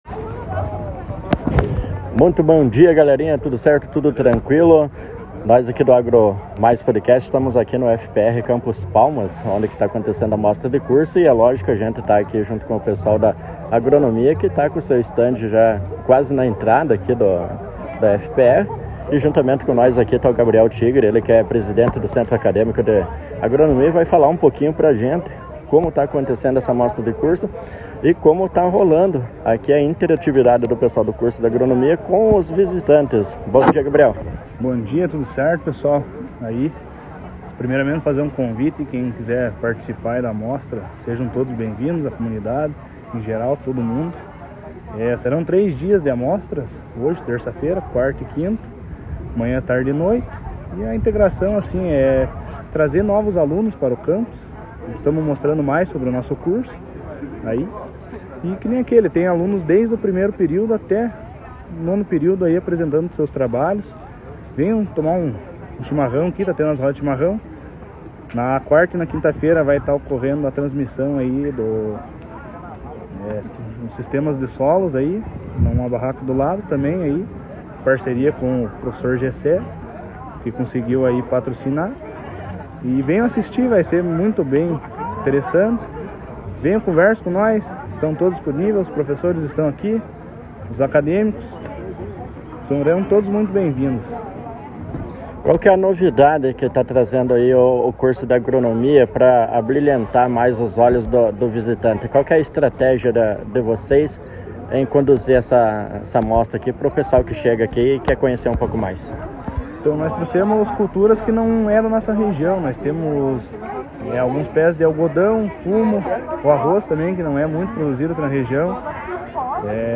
entrevista-amostra-curso-agronomia.mp3